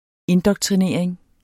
Udtale [ ˈendʌgtʁiˌneɐ̯ˀeŋ ]